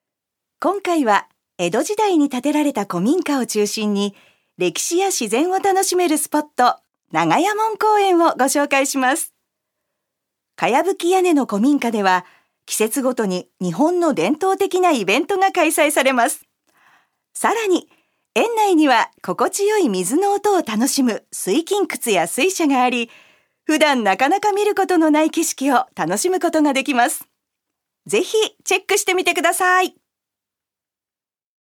女性タレント
ナレーション７